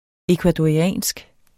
ecuadoriansk adjektiv Bøjning -, -e Udtale [ εkvadɒiˈæˀnsg ] Betydninger fra Ecuador; vedr.